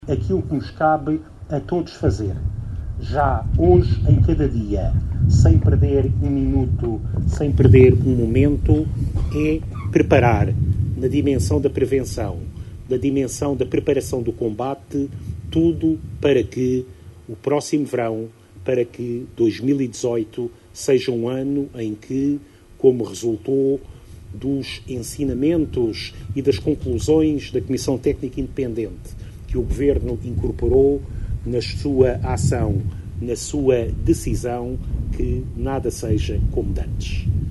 A visita, que contou também com a presença do Ministro da Administração Interna, Eduardo Cabrita, iniciou-se na freguesia de Ancora, seguindo depois para Riba de Âncora onde teve lugar a cerimónia de assinatura do protocolo para a contratação e funcionamento das equipas de intervenção permanente que irão ficar sediadas nas corporações de bombeiros de Caminha e Vila Praia de Âncora.